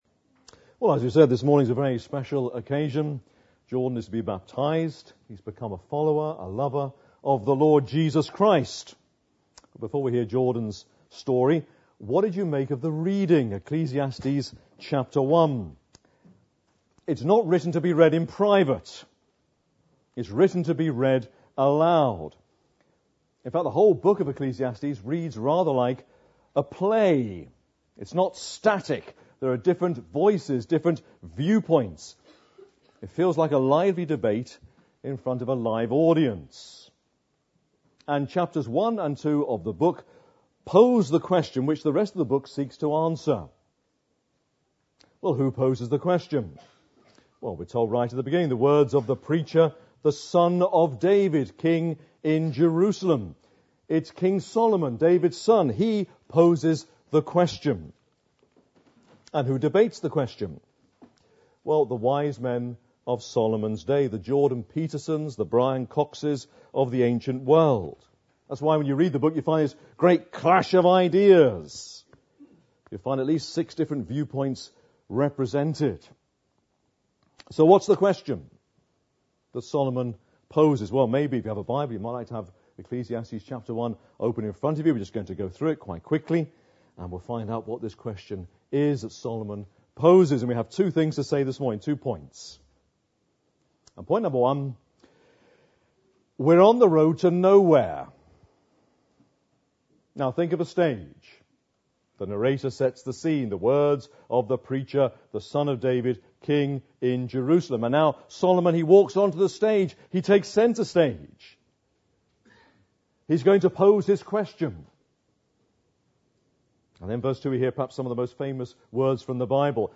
Baptism; We're on the road to nowhere - Ecclesiastes 1